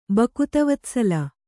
♪ bakuta vatsala